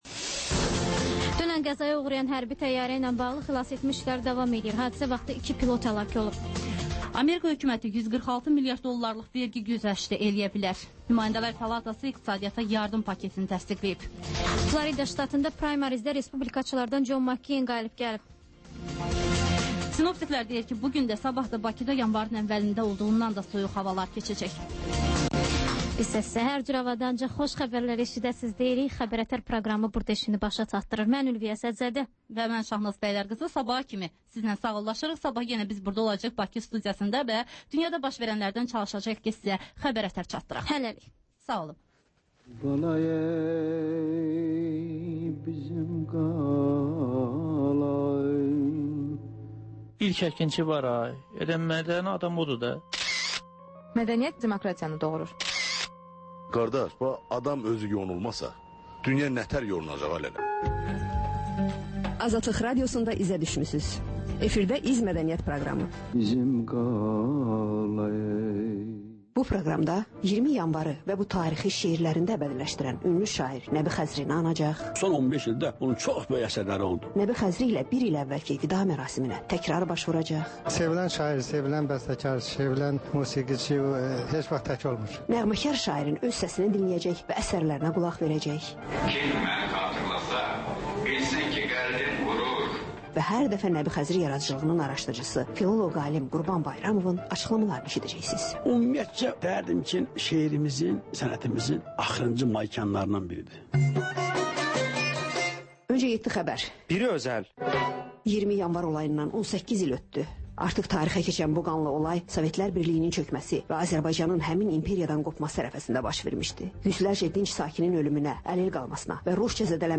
XÜSUSİ REPORTAJ